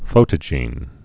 (fōtə-jēn)